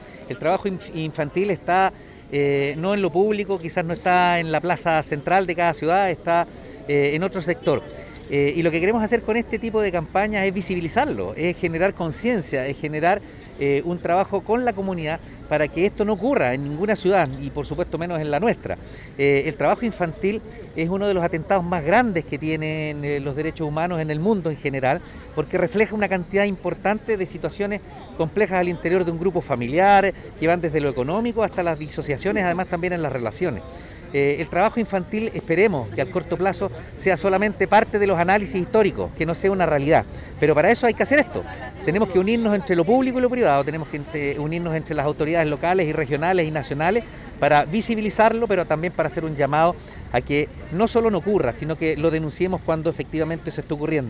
ALCALDE-DE-QUILLOTA-Oscar-Calderon.mp3